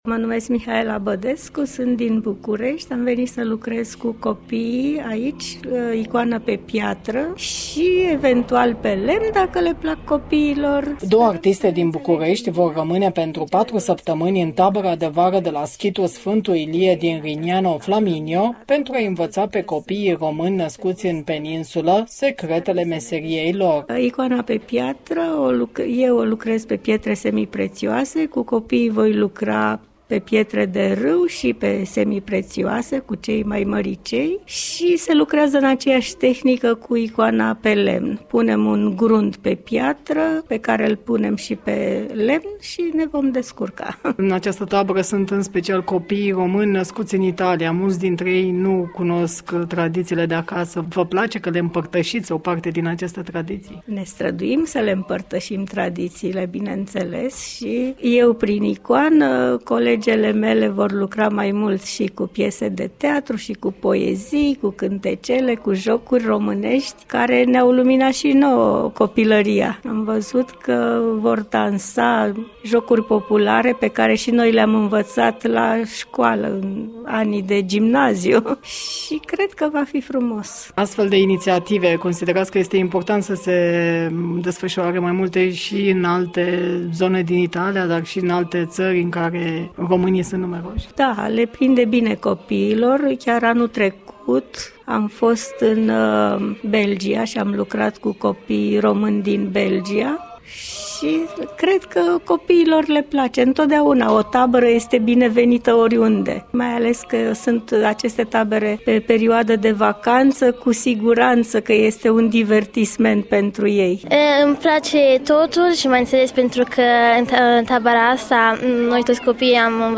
a realizat un reportaj pentru emisiunea ”Weekend cu prieteni”.